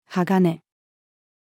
鋼-female.mp3